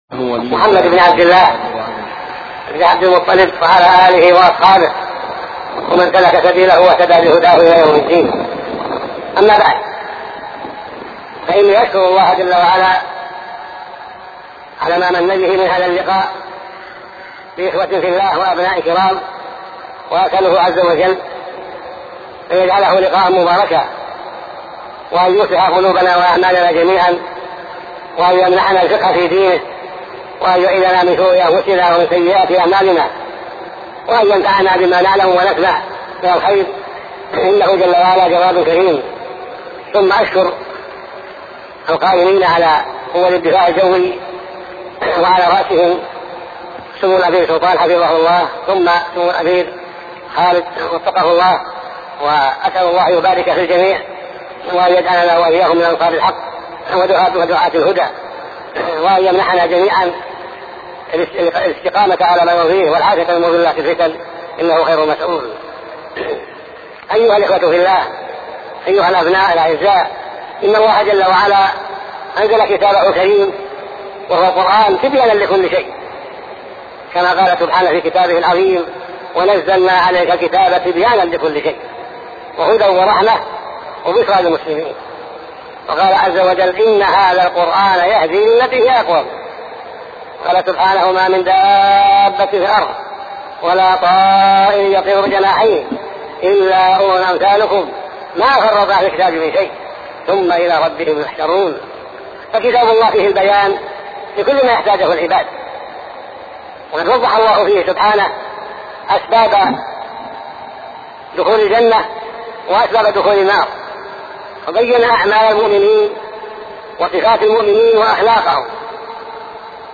شبكة المعرفة الإسلامية | الدروس | نواقض الإسلام |عبدالعزيز بن عبداللة بن باز